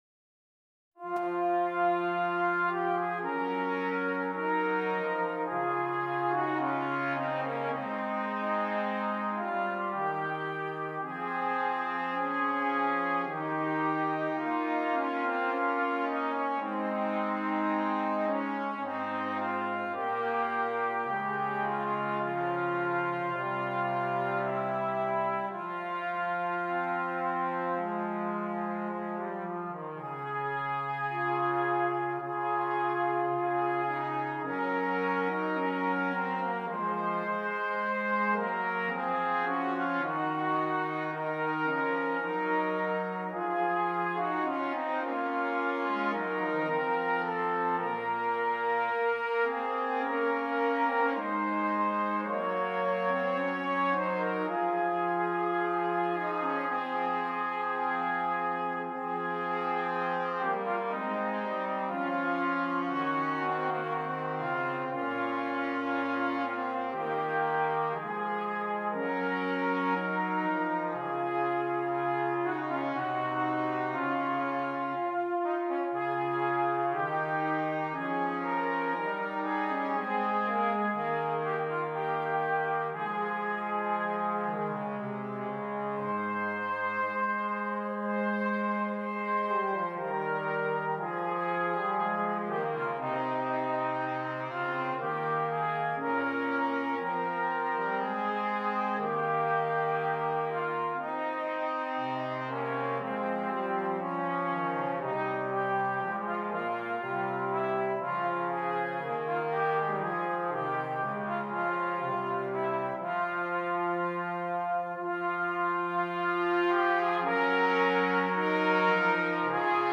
Brass Trio
This is an arrangement of one chanson.